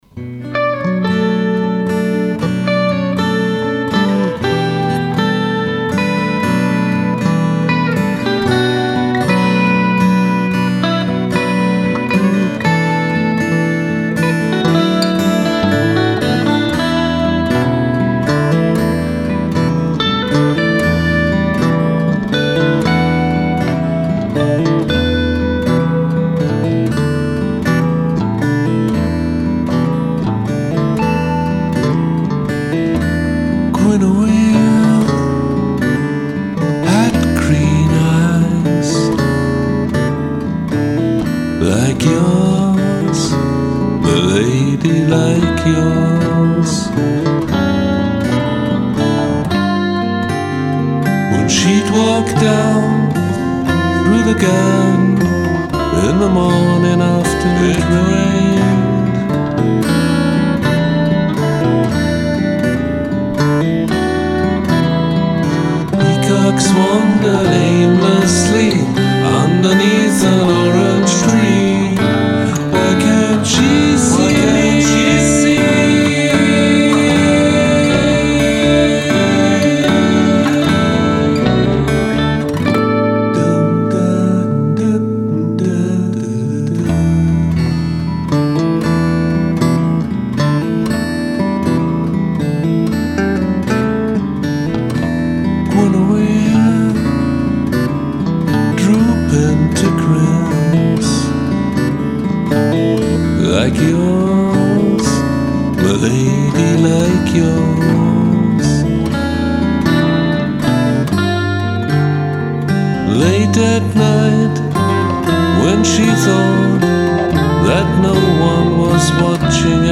Acoustic Guitar
Electric Guitar, Bass Guitar, Vocals